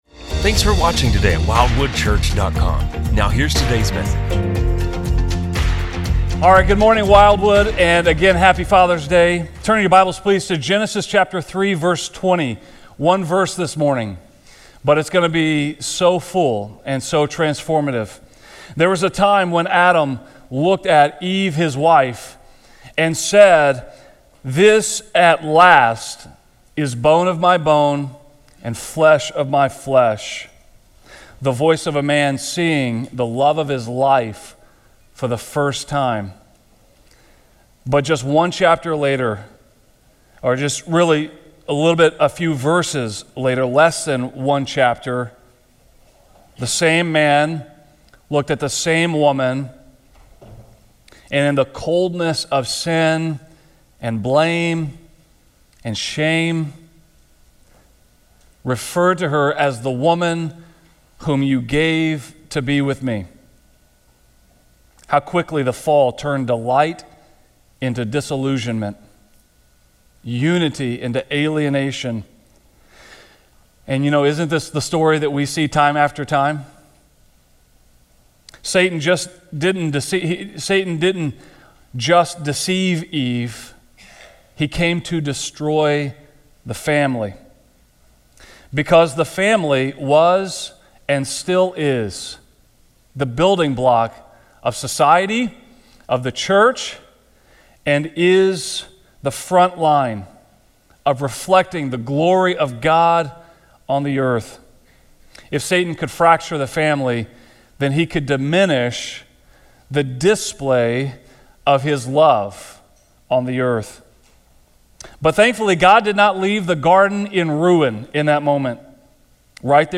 A message from the series "Core Values."